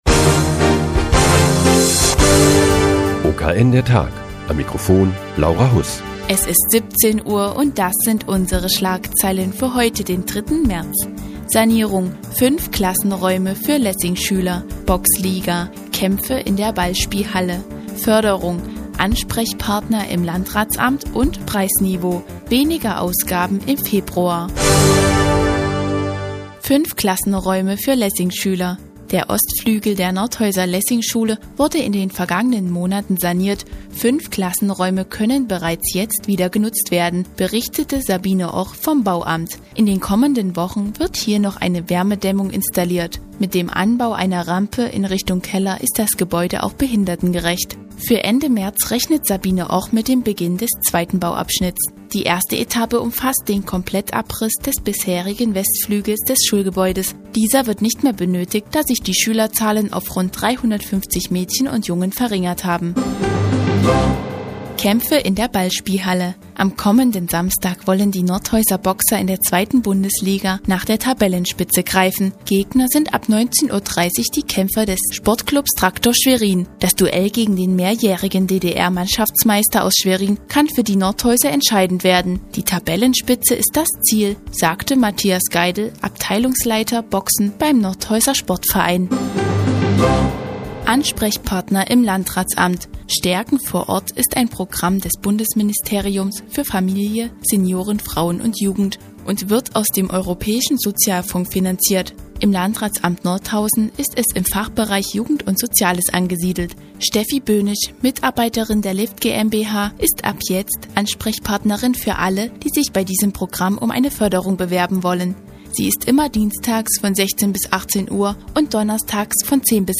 Die tägliche Nachrichtensendung des OKN ist nun auch in der nnz zu hören. Heute geht es um die Sanierung von Klassenräumen in der Nordhäuser Lessingschule und den Boxkampf in der Ballspielhalle am kommenden Samstag.